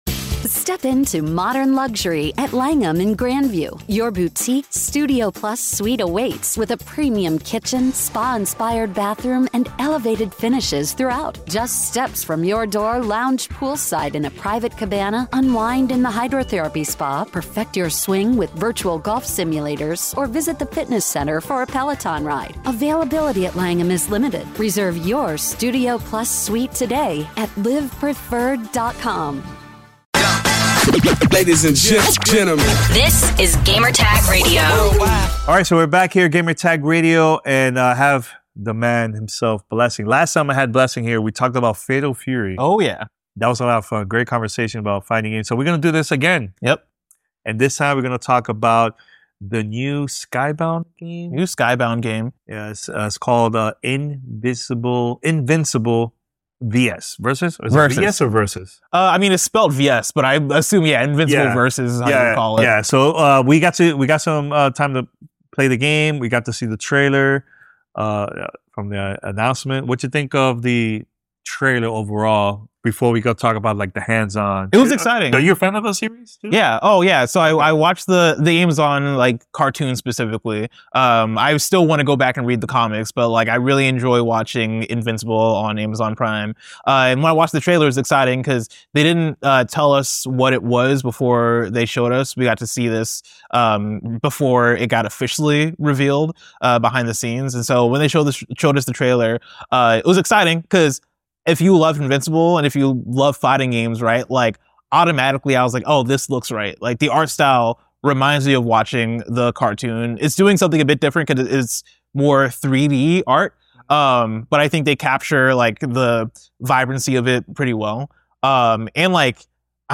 Invincible VS Interview with QuarterUp